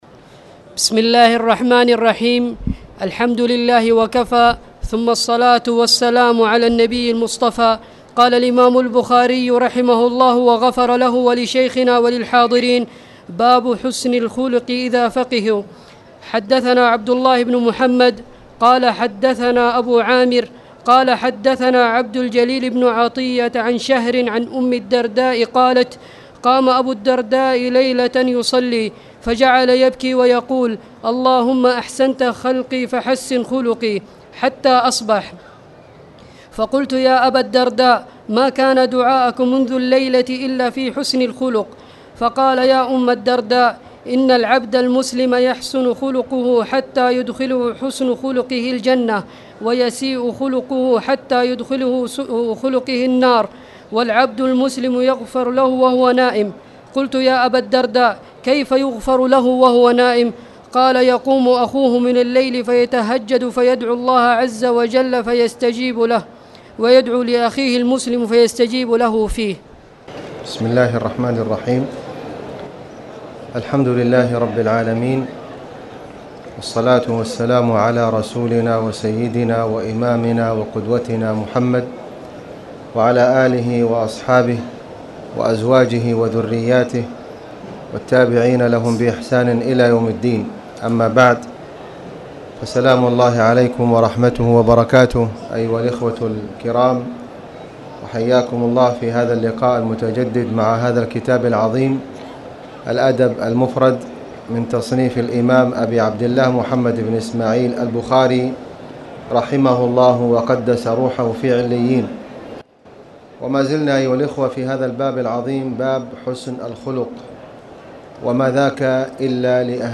تاريخ النشر ١٩ شعبان ١٤٣٨ هـ المكان: المسجد الحرام الشيخ: خالد بن علي الغامدي خالد بن علي الغامدي باب حسن الخلق اذا فقهوا